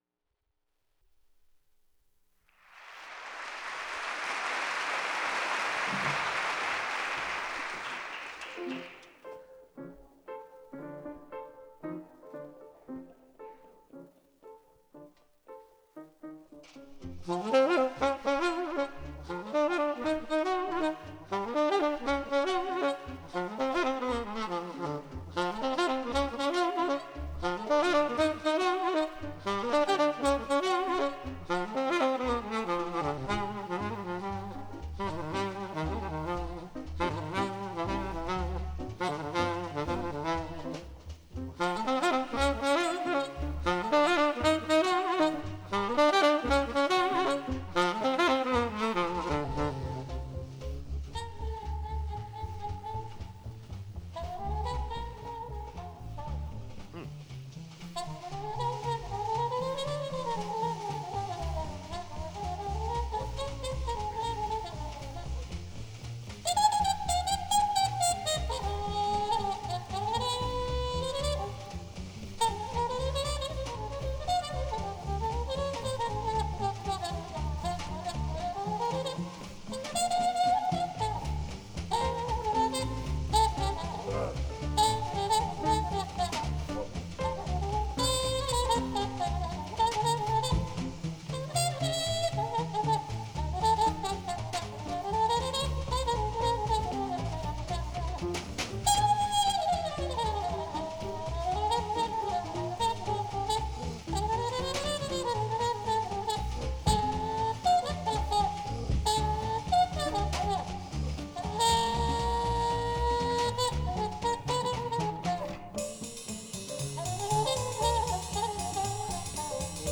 Tenor Saxophone
Trumpet
Drums
Recorded at the Opera House, Chicago